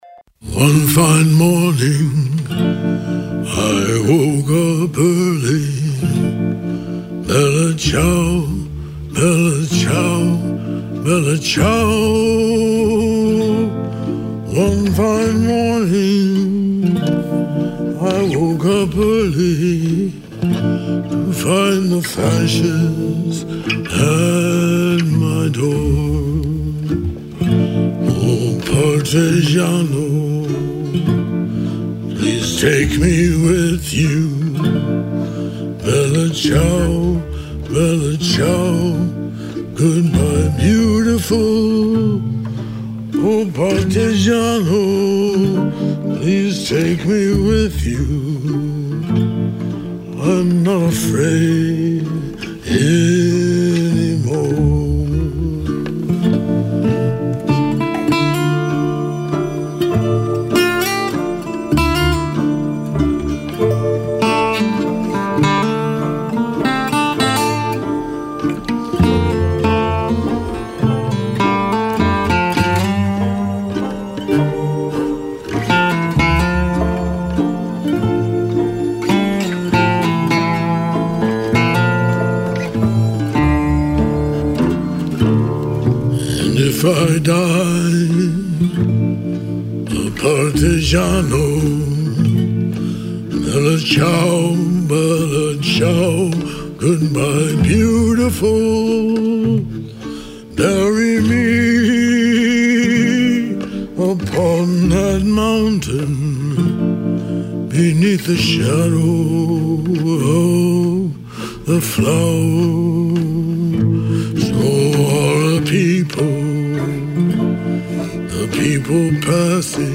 Ci muoveremo seguendo i percorsi segreti che legano le opere l’una all’altra, come a unire una serie di puntini immaginari su una mappa del tesoro. Memoir e saggi, fiction e non fiction, poesia (moltissima poesia), musica classica, folk, pop e r’n’r, mescolati insieme per provare a rimettere a fuoco la centralità dell’esperienza umana e del racconto che siamo in grado di farne.